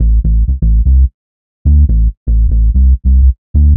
FU_128_ACD_BASS_09 [..> 2025-11-16 12:42  2.1M